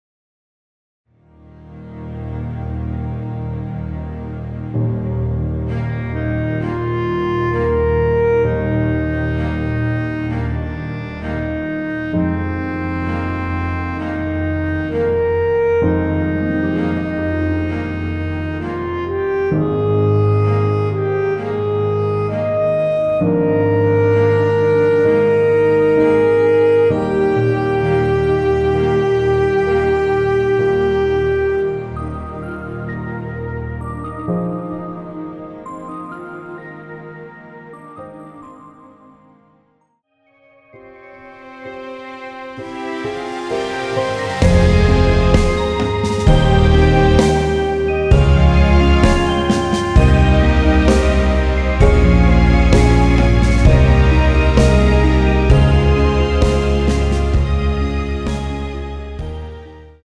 원키에서(-1)내린 멜로디 포함된 MR입니다.
Eb
앞부분30초, 뒷부분30초씩 편집해서 올려 드리고 있습니다.
중간에 음이 끈어지고 다시 나오는 이유는